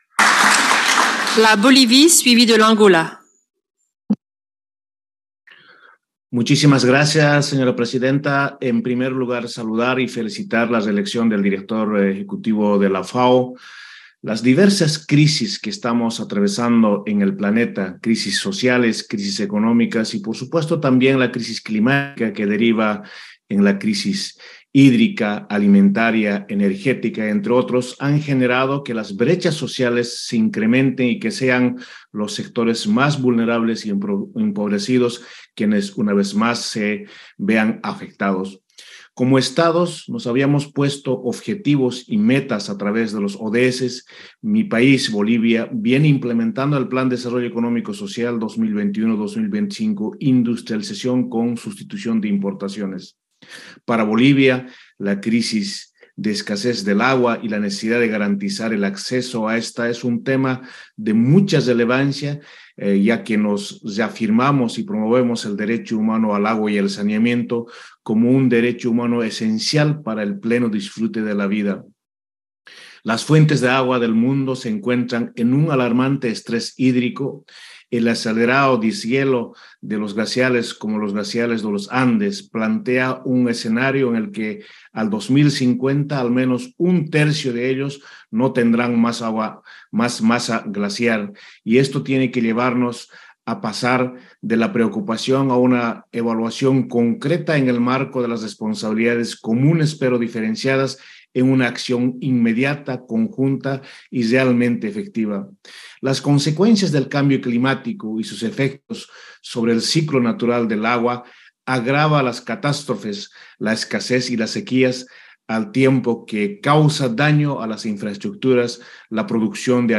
GENERAL DEBATE
Addresses and Statements
H.E. Erwin Freddy Mamani Machaca, Vice-Minister for Foreign Affairs
(Plenary – Español)